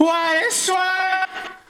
8 Harsh Realm Vox What Is Work.wav